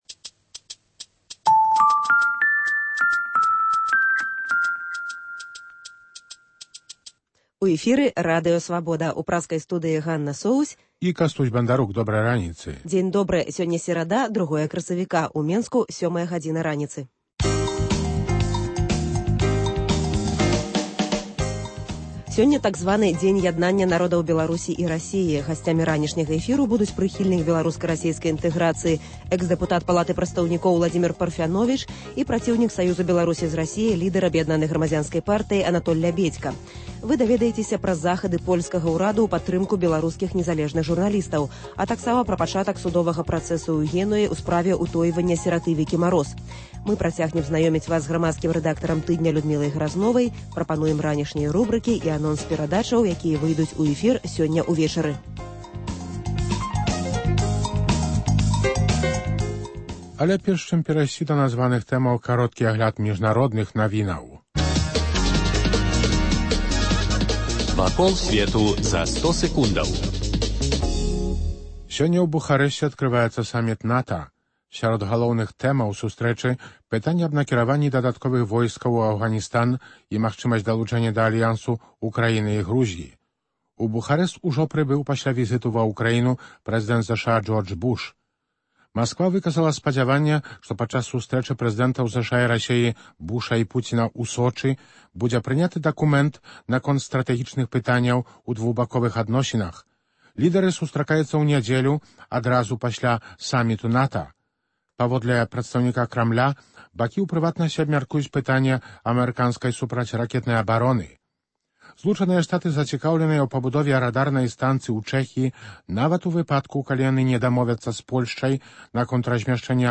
Ранішні жывы эфір
Наўпроставы эфір з Уладзімерам Парфяновічам і Анатолем Лябедзькам: як мусяць будавацца адносіны Беларусі й Расеі?